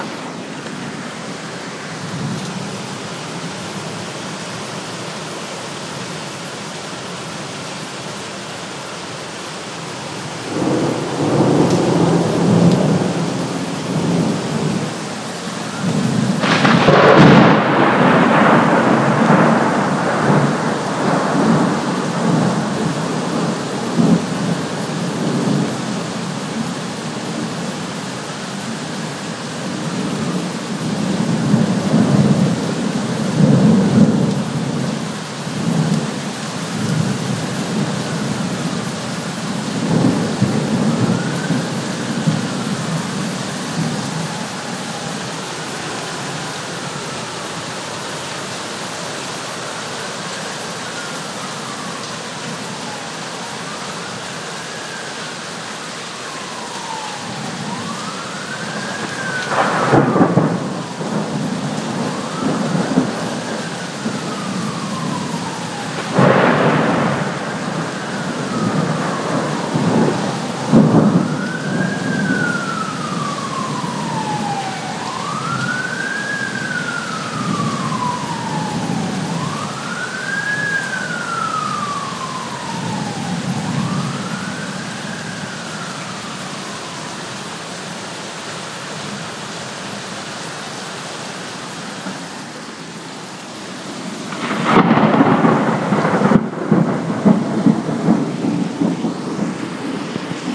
Boston Thunderstorm June 1, 2011